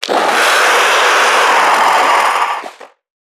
NPC_Creatures_Vocalisations_Infected [17].wav